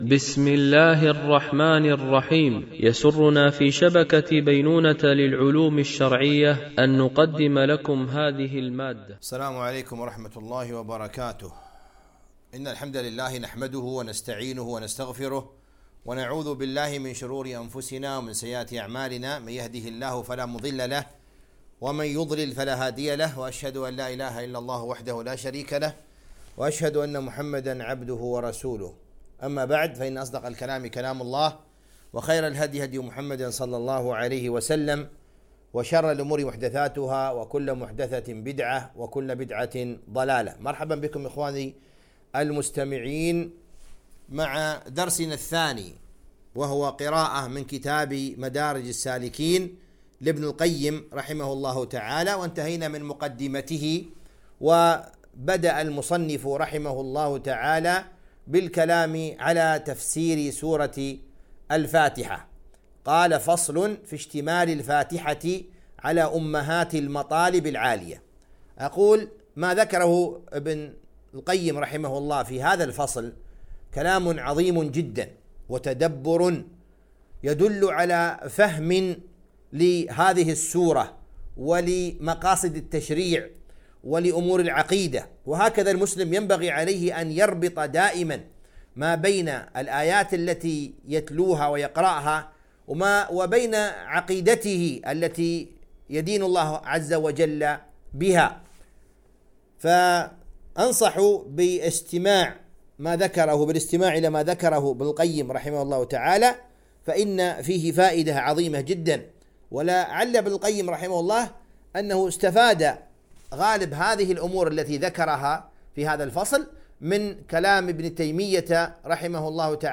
قراءة من كتاب مدارج السالكين - الدرس 02